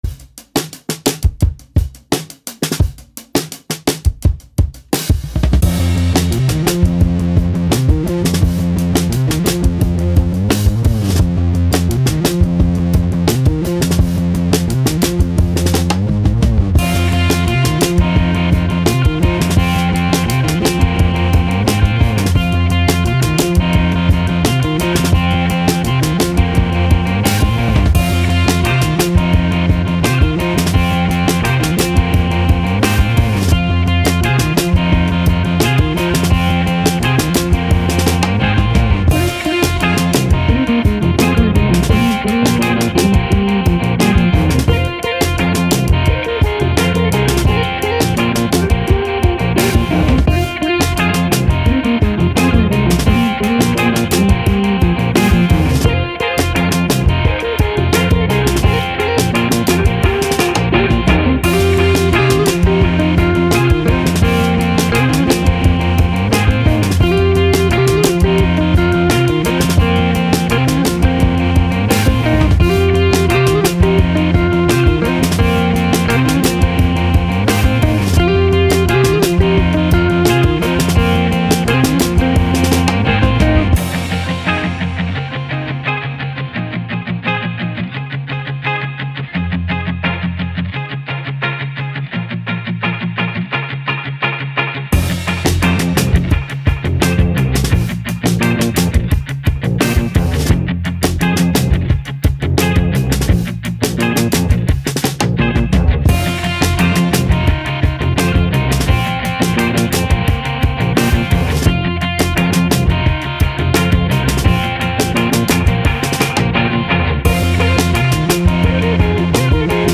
Indie Rock, Action, Upbeat, Energetic